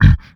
MONSTER_Grunt_Breath_02_mono.wav